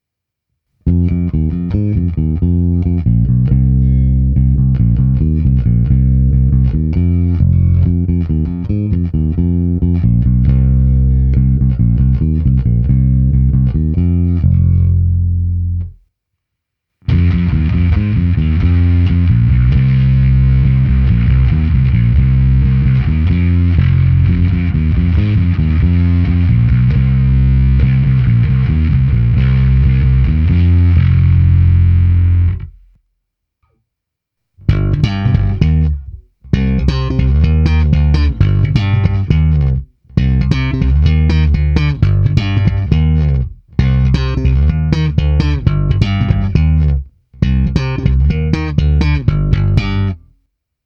Zvonivý, vrnivý, pevný.
Není-li uvedeno jinak, následující nahrávky jsou provedeny rovnou do zvukovky a kromě normalizace ponechány bez dodatečných úprav.